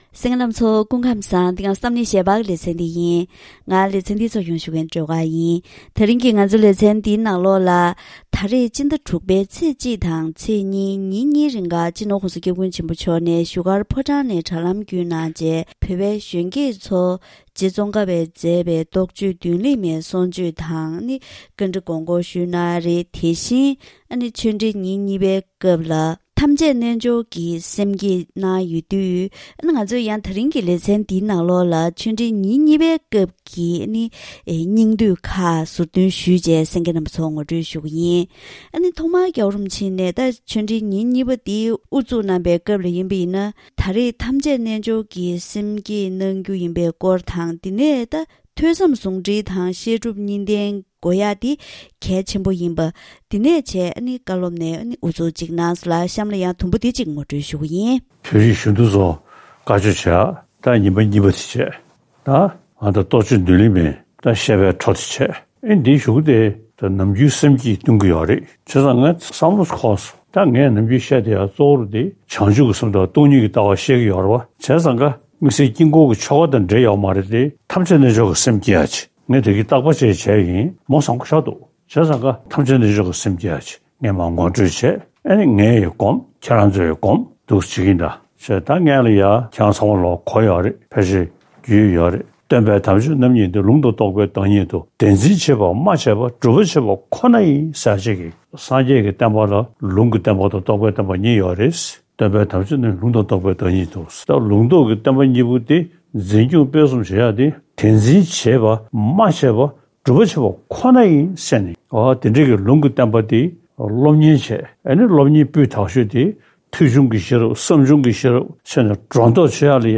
སྤྱི་ནོར་༧གོང་ས་༧སྐྱབས་མགོན་ཆེན་པོ་མཆོག་གིས་བཞུགས་སྒར་ཕོ་བྲང་ནས་དྲ་ལམ་ཐོག་བོད་པའི་གཞོན་སྐྱེས་ཚོར་ཆོས་འབྲེལ་བཀའ་དྲིན་སྩལ་བ།